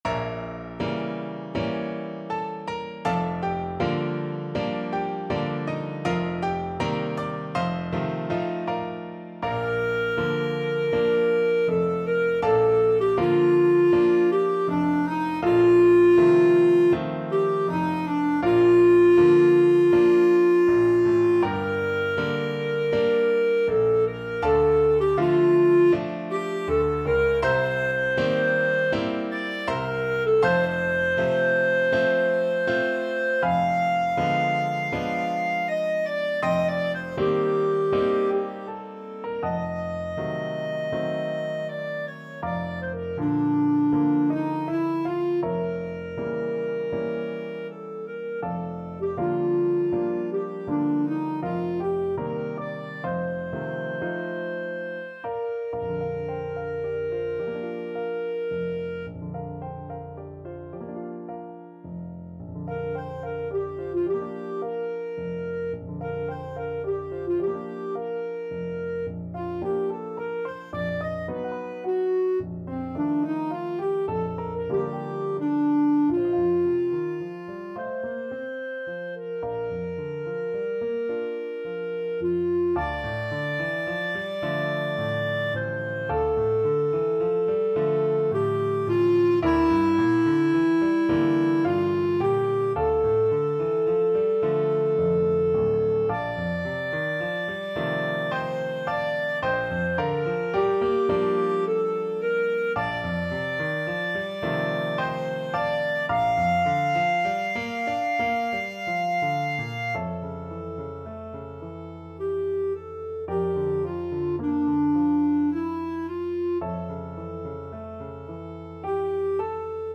Clarinet version